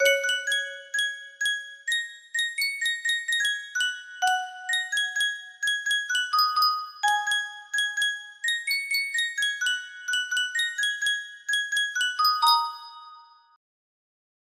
Yunsheng Music Box - Buddhism Great Compassion Mantra 2488 music box melody
Full range 60